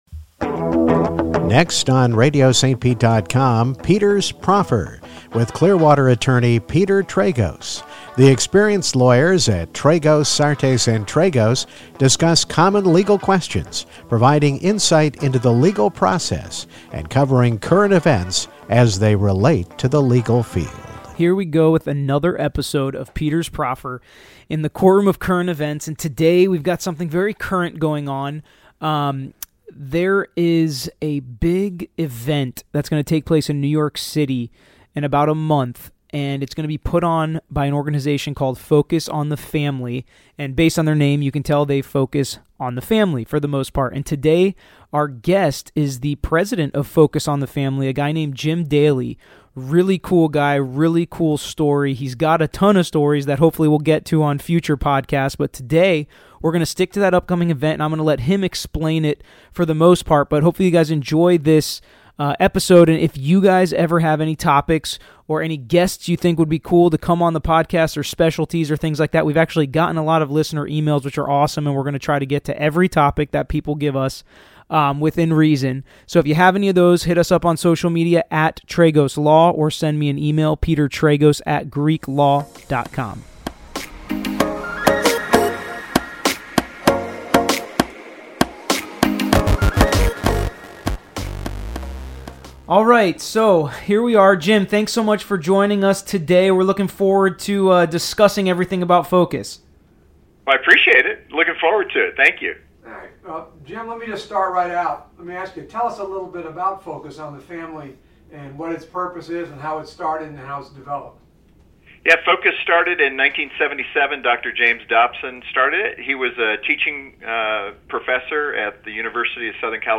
In this episode of Peter’s Proffer, we're joined by special guest Jim Daly, President of Focus on the Family.